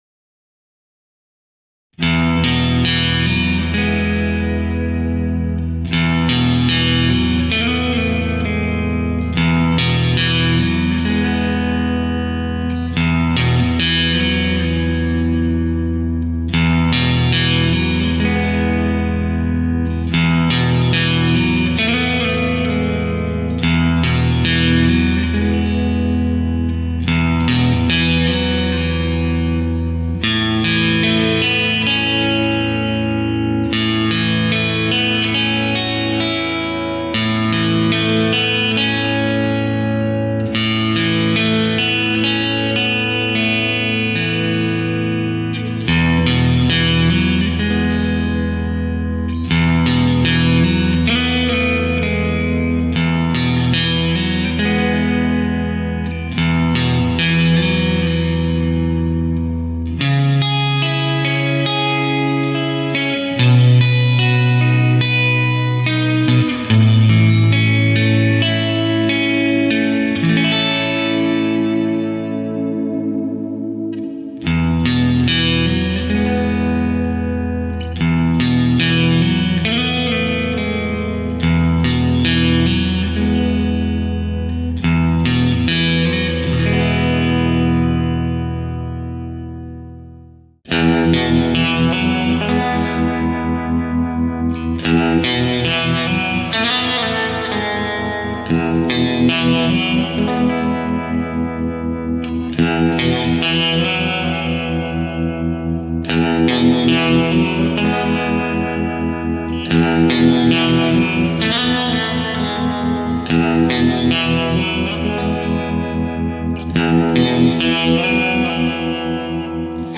The 1st half is the ReVibe with reverb only
The 2nd half is the Revibe with Reverb and Vibrato
It's a really simple diddy, nothing special,  but the Revibe gives it a huge room feel.
This is a direct recording, No Speakers, no mics
Guitar -> ReVibe -> Tube preamp -> sound card line in -> Guitar rig using a HiWatt amp and cabinet -> recorded in Mixcraft 6